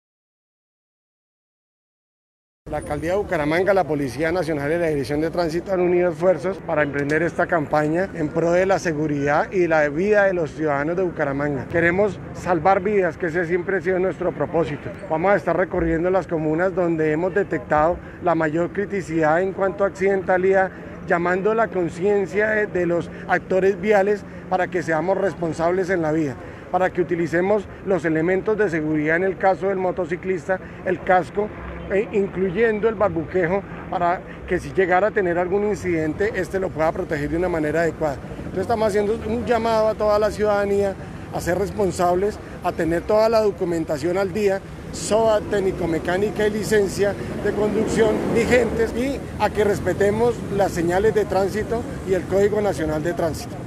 Juan Pablo Ruiz, director de Tránsito de Bucaramanga / Luis Ernesto García, comandante de la Mebuc
Juan-Pablo-Ruiz-director-de-Tránsito-de-Bucaramanga.mp3